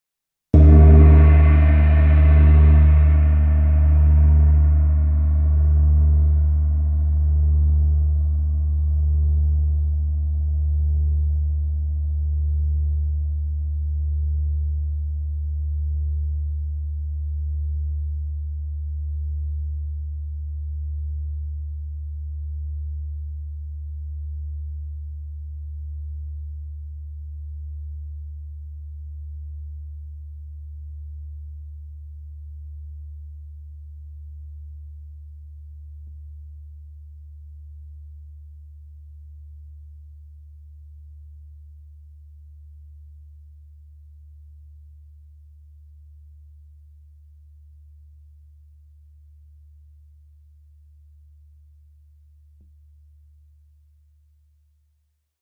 ZENN gongs are handmade to the highest quality and come in a wide range of sizes, from 10 to 38, to suit a variety of uses and situations.